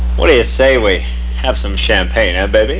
champagn.mp3